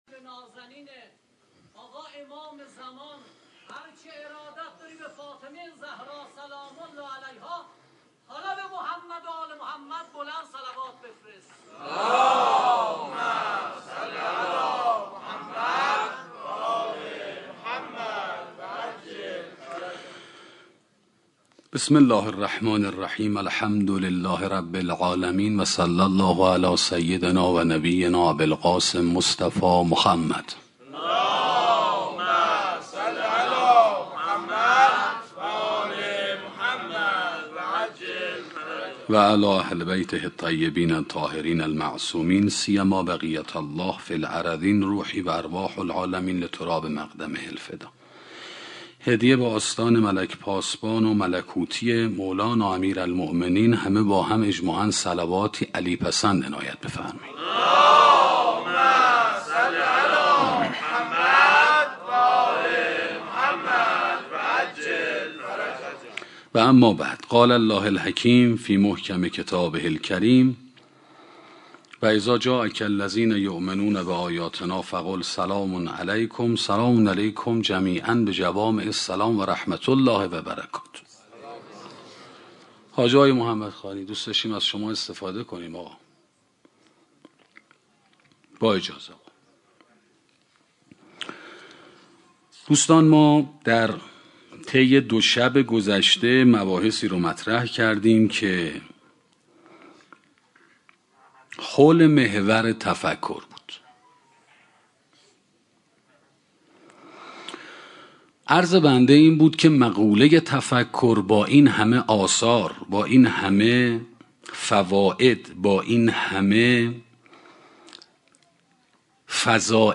سخنرانی تصویر نظام هستی 3 - موسسه مودت